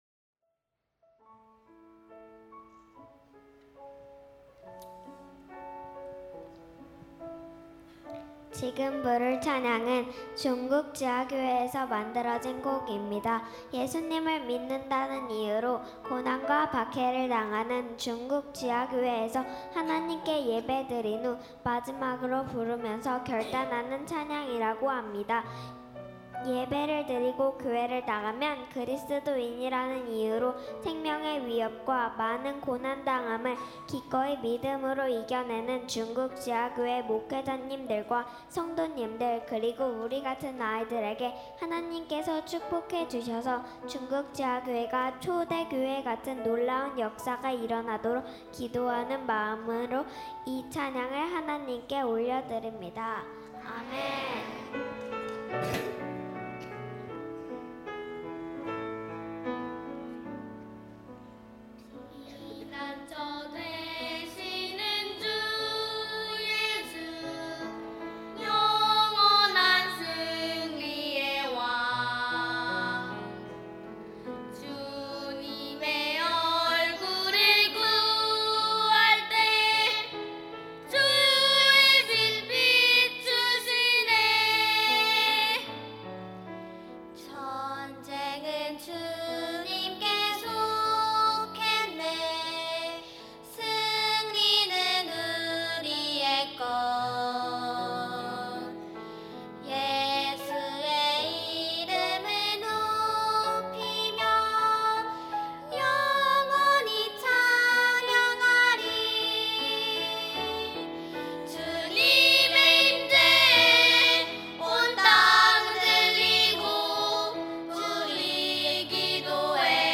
# 첨부 1.02 피난처 되신 주 예수(아동부 연합찬양대).mp3